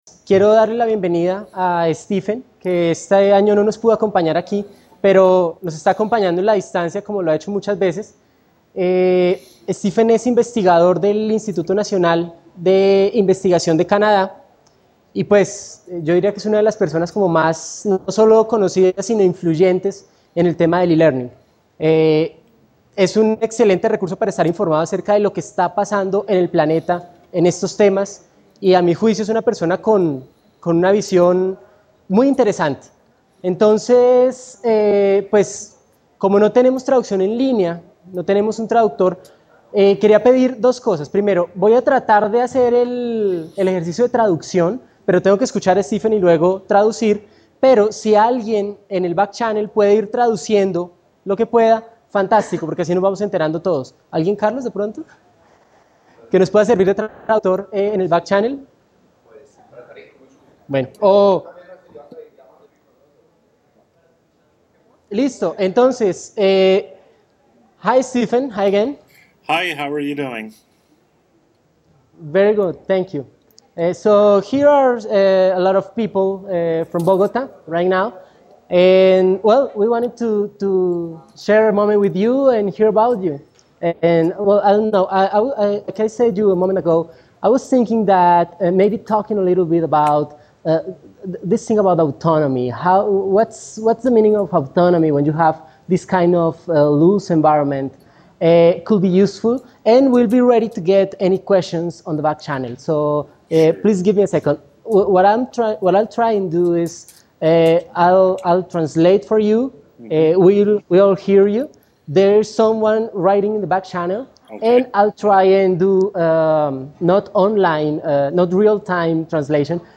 Question and answer session with participants of the Colombia EduCamp 2008 from Bogota.